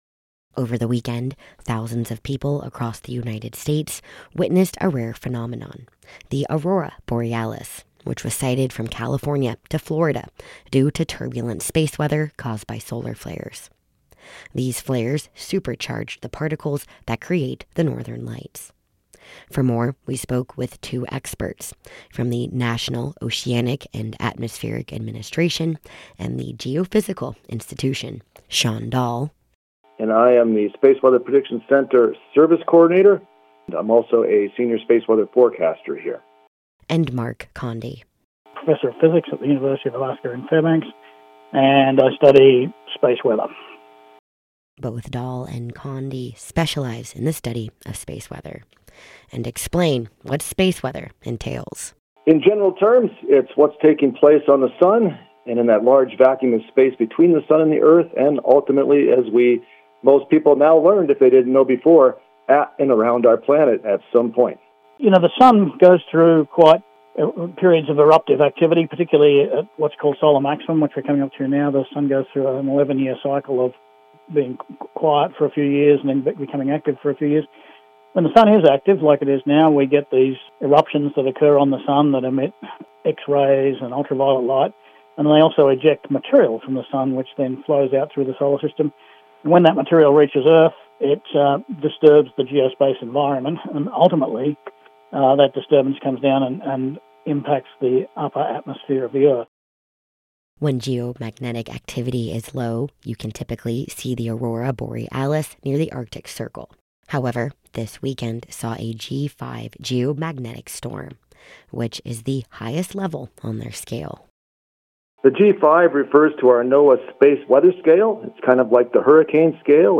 This weekend, a G5 geomagnetic storm, triggered by solar flares, supercharged the particles of the aurora borealis, making the rare spectacle visible from California to Florida. For insights into this phenomenon, we spoke with experts from the National Oceanic and Atmospheric Administration and the Geophysical Institute in this report...